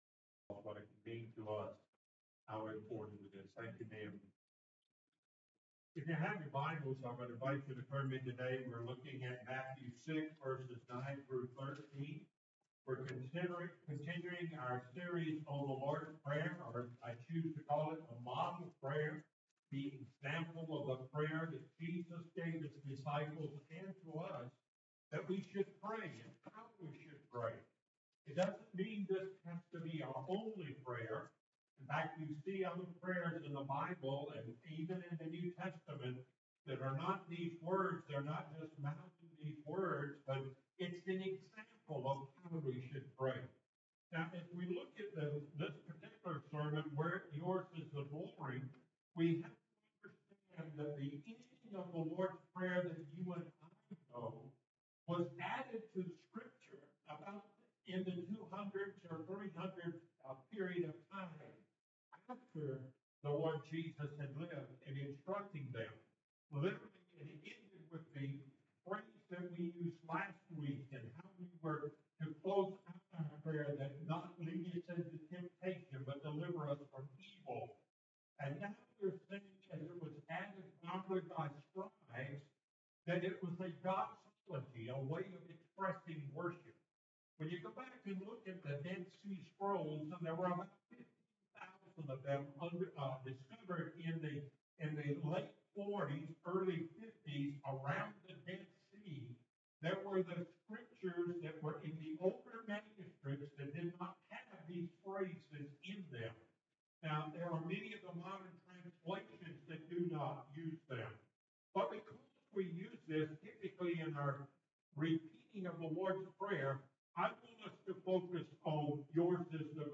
The Model Prayer Passage: Matthew 6:9-13 Service Type: Sunday Morning Topics